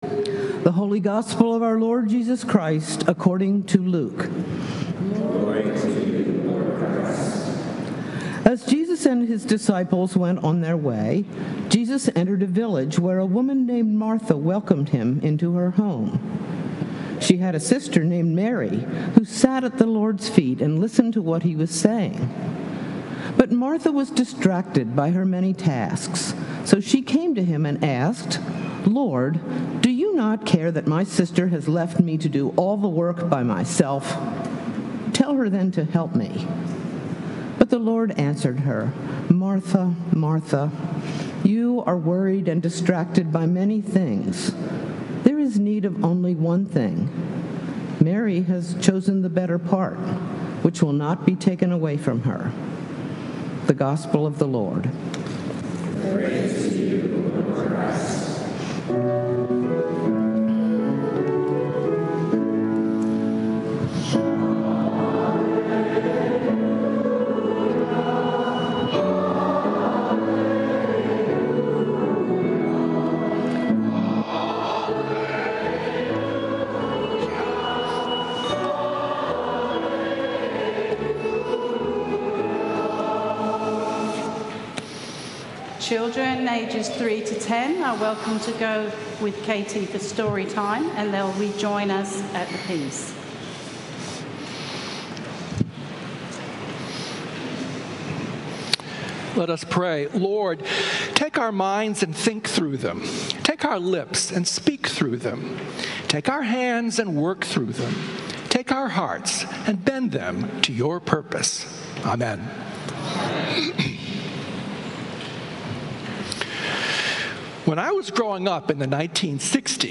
Sermons from St. Columba's in Washington, D.C.